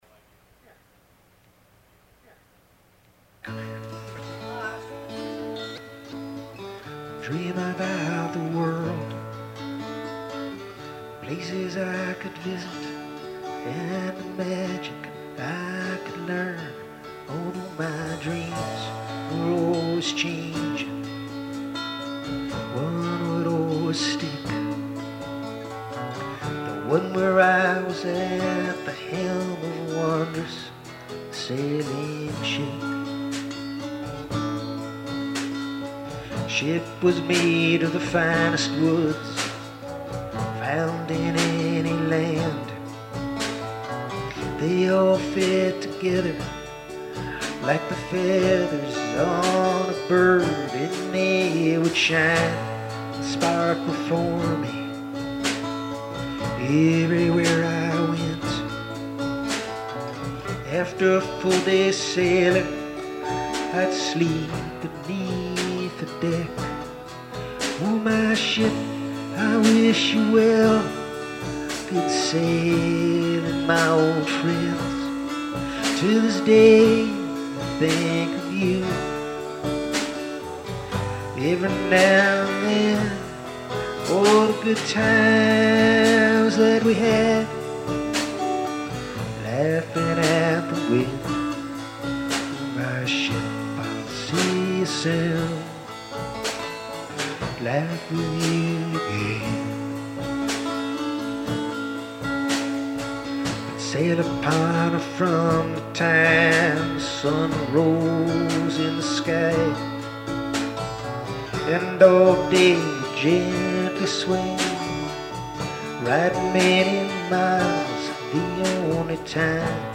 Vintage Live & Rehearsal Recordings
Recorded during several practice sessions in January, 2001.